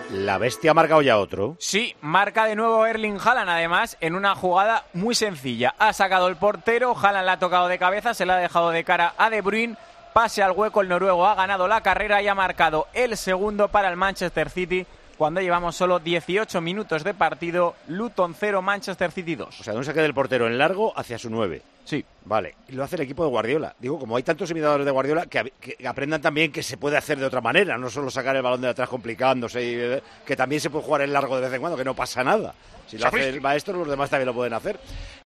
El presentador de Tiempo de Juego halagó la capacidad de Guardiola para saber adapatar su estilo de juego al del fútbol en Inglaterra durante las últimas temporadas.